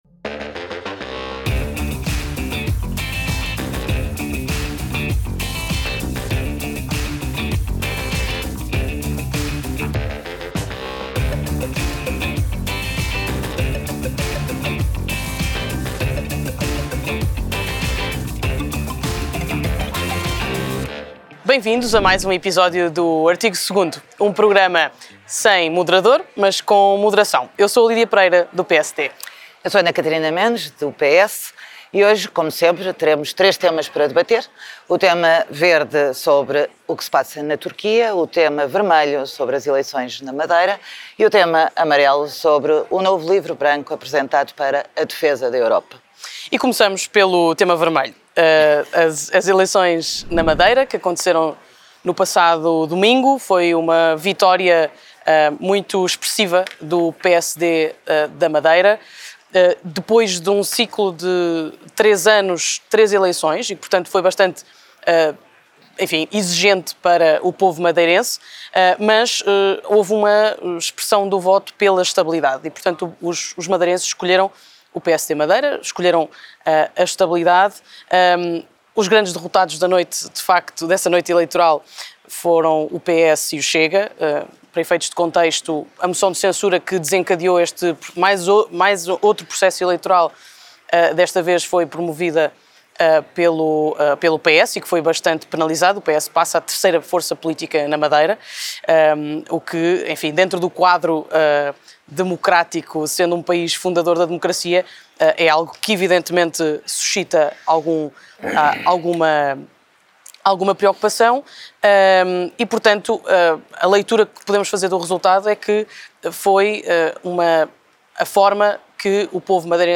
🎙Artigo 2.º é o novo podcast mensal que junta Lídia Pereira e Ana Catarina Mendes num formato de debate dinâmico e envolvente. Inspirado no Artigo 2.º do Tratado da União Europeia, que promove os valores da liberdade, democracia e respeito pelos direitos humanos, este programa traz discussões aprofundadas sobre temas políticos e sociais relevantes.